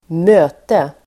Uttal: [²m'ö:te]